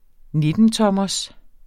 Udtale [ ˈnedənˌtʌmʌs ]